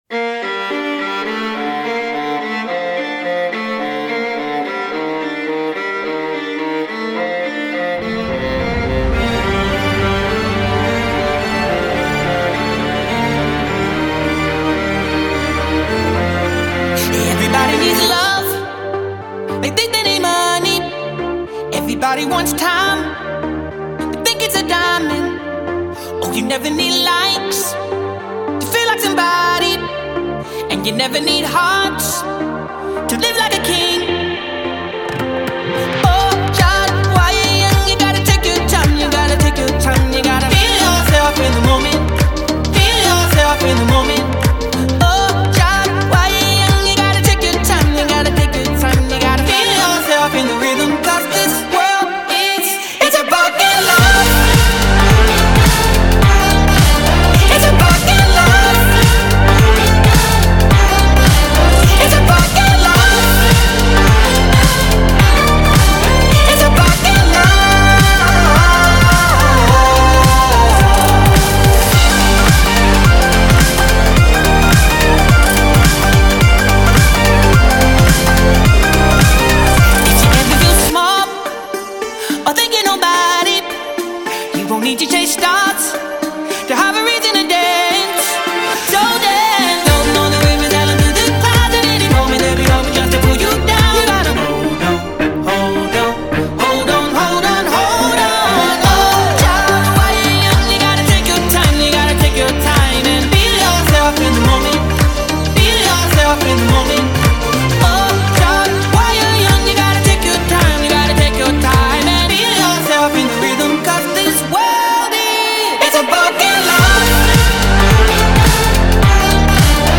выполненная в жанре электроник и поп.